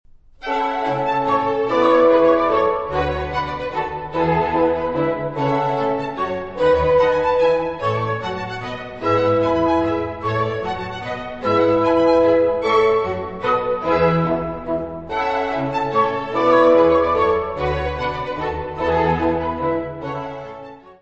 Notes:  Gravado no Concert Hall, New Broadcasting House, Manchester, de 26 a 27 de Outubro, 1993; Disponível na Biblioteca Municipal Orlando Ribeiro - Serviço de Fonoteca
Music Category/Genre:  Classical Music
Menuetto.